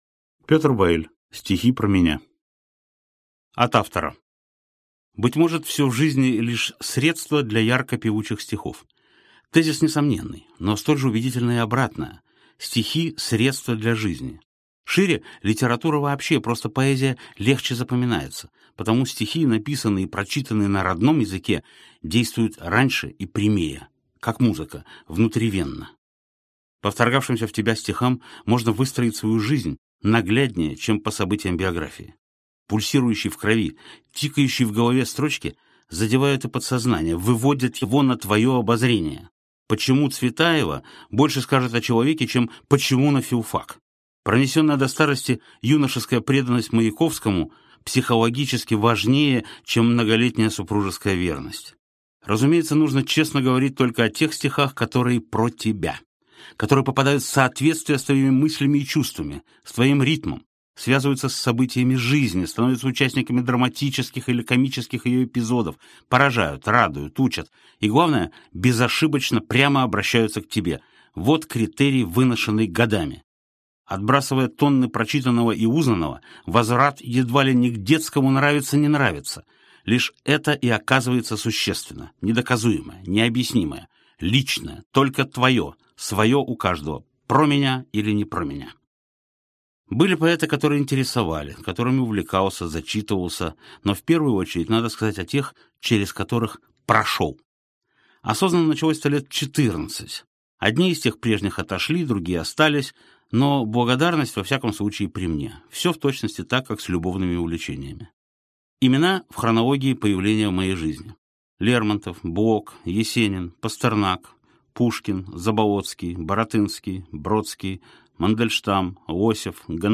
Aудиокнига Стихи про меня Автор Петр Вайль Читает аудиокнигу Петр Вайль.